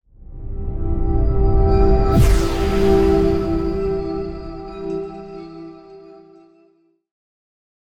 moonbeam-outro-v1-003.ogg